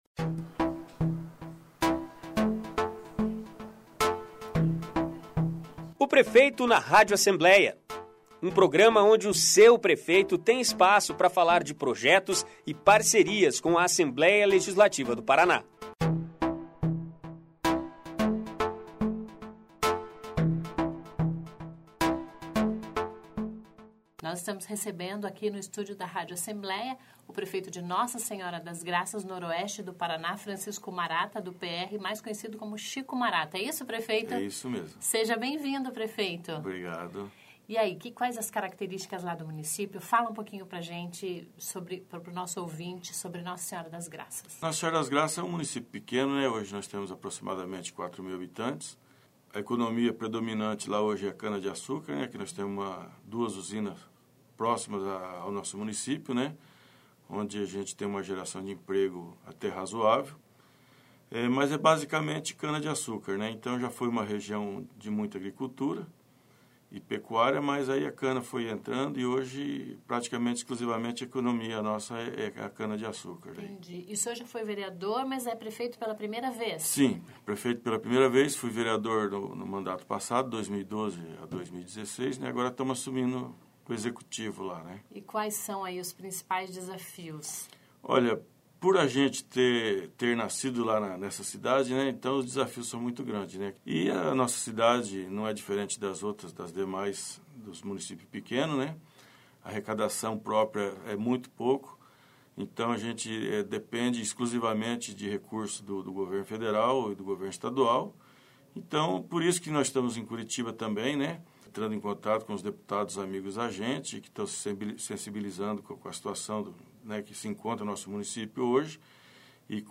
Ouça na íntegra a entrevista com o prefeito de Nossa Senhora das Graças, Chico Marata (PR).